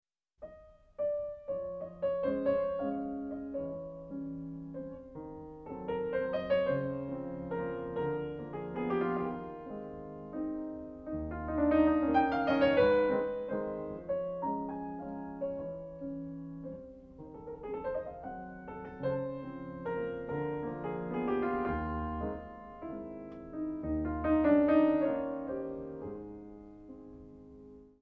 A flat major